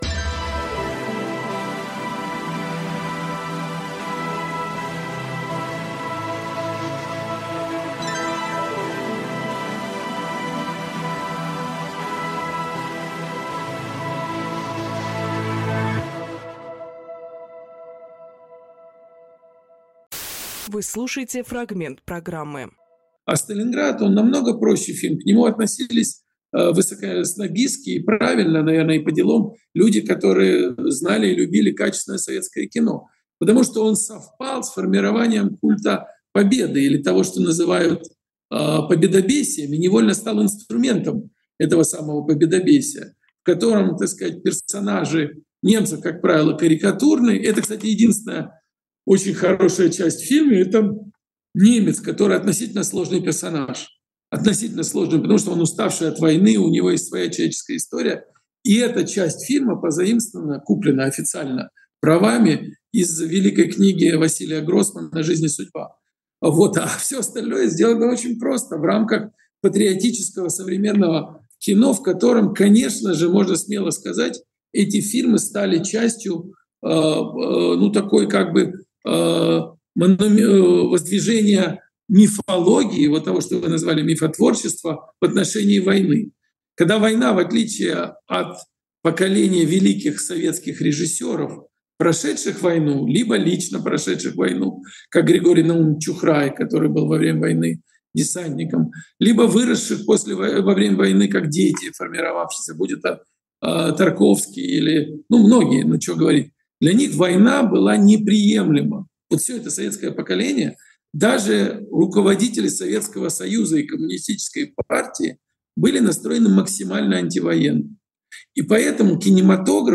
Александр Роднянскийкинопродюсер
Фрагмент эфира от 18.06.25